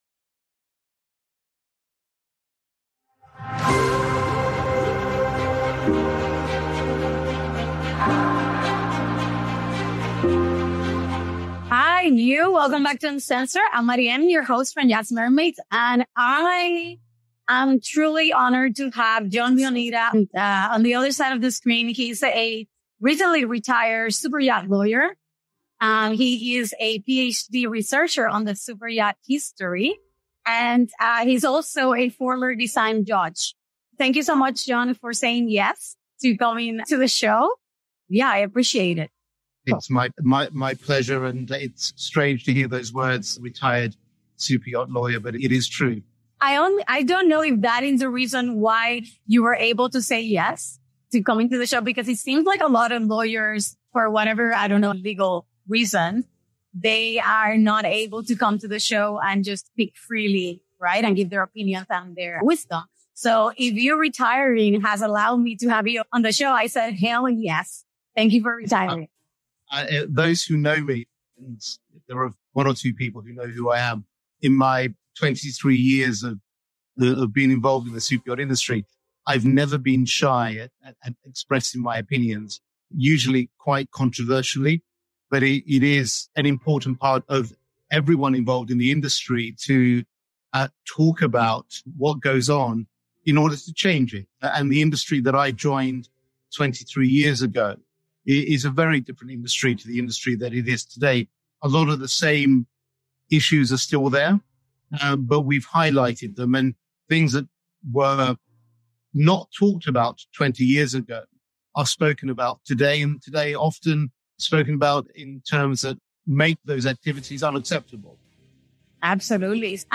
Join the conversation that goes beyond the surface, exploring the past, present, and future of the yachting industry. Discover the challenges, cultural influences, legal nuances, and the vision for a future that preserves the industry's charm while embracing positive changes.